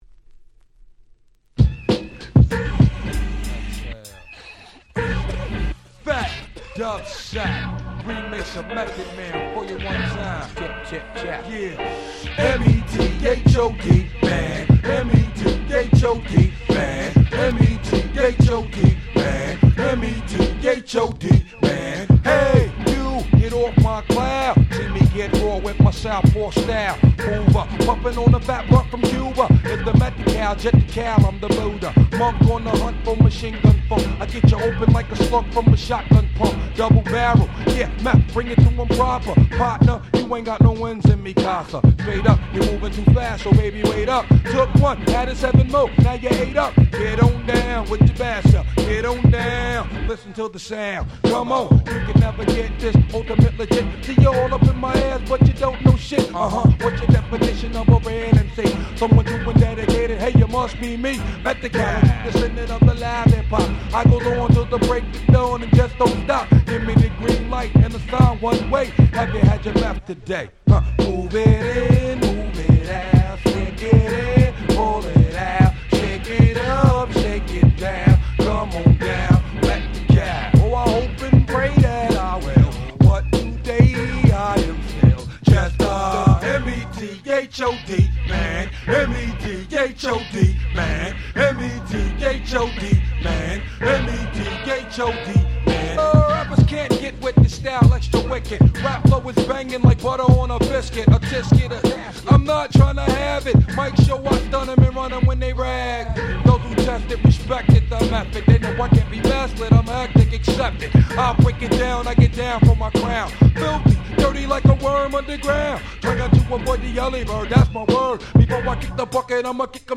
93' Super Hip Hop Classics !!